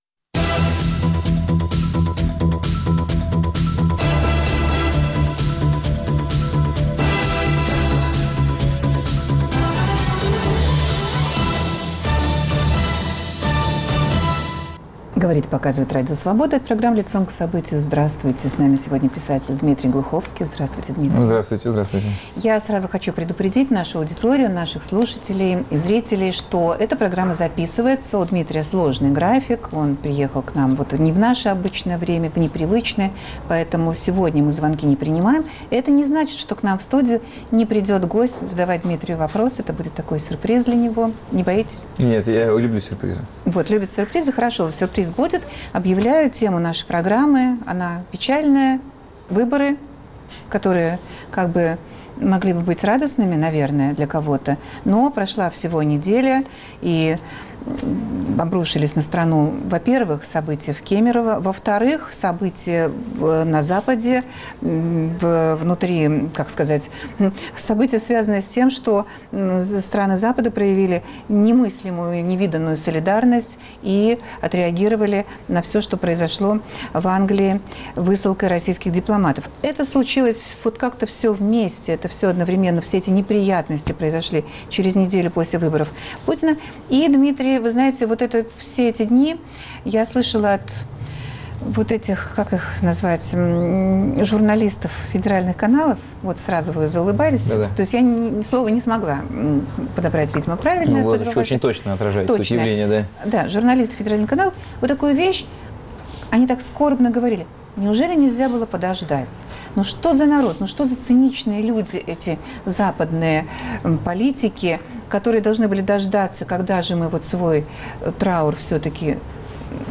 Лидер в той или иной сфере общественной жизни - человек известный и информированный - под перекрестным огнем вопросов журналистов. Дмитрий Глуховский о современном политическом моменте и о своей реконструкции будущего.